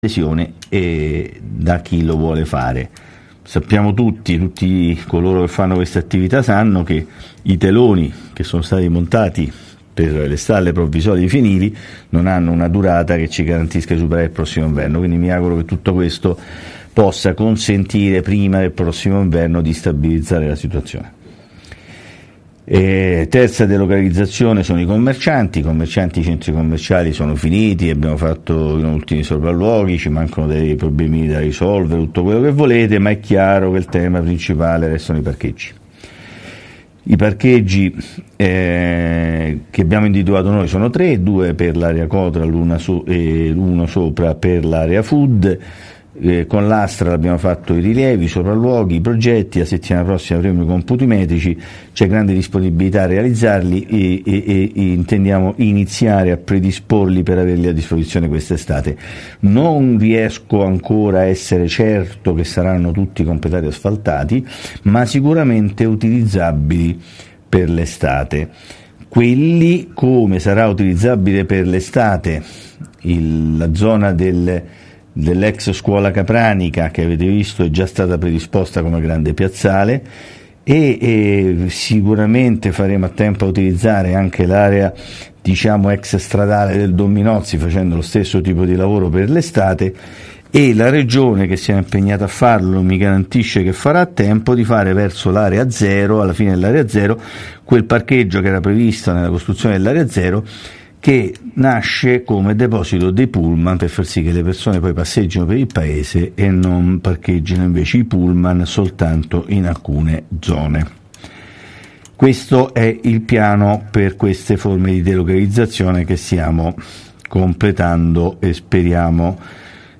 Di seguito il messaggio audio del Sindaco  Filippo Palombini del 1 GIUGNO 2018